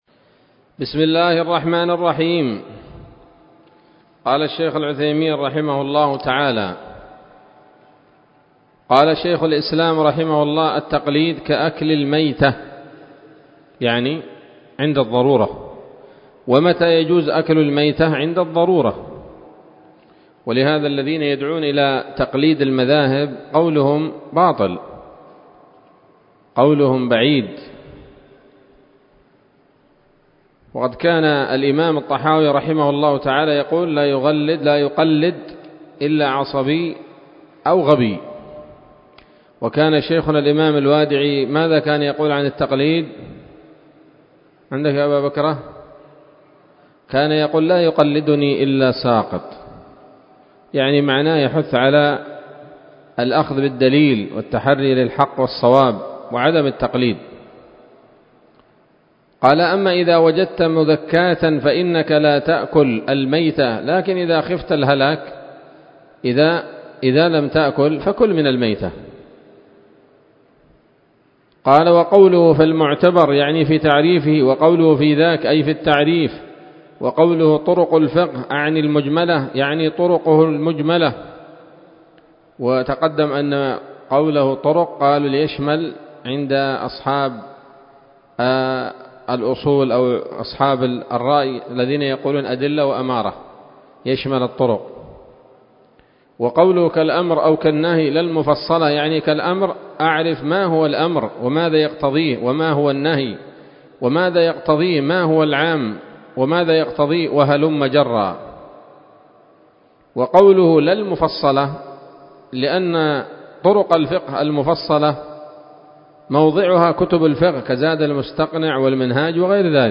الدرس السابع والعشرون من شرح نظم الورقات للعلامة العثيمين رحمه الله تعالى